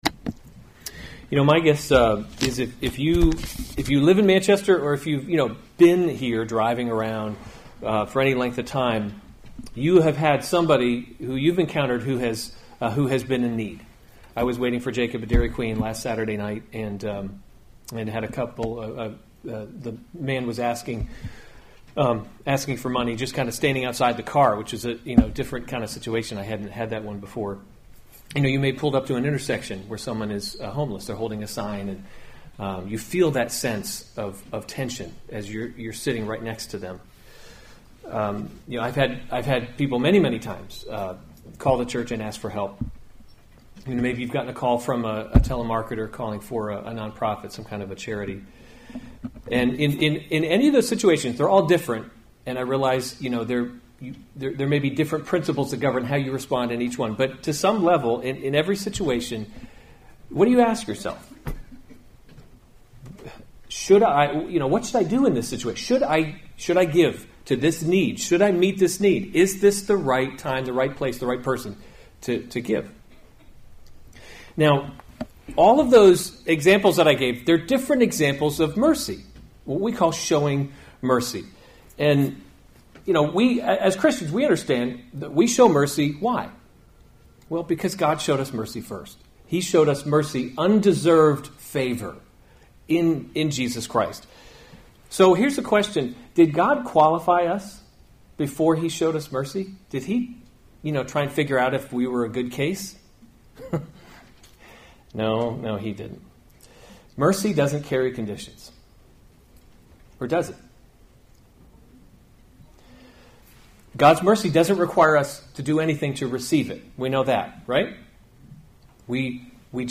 May 13, 2017 1 Timothy – Leading by Example series Weekly Sunday Service Save/Download this sermon 1 Timothy 5:9-16 Other sermons from 1 Timothy 9 Let a widow be enrolled if […]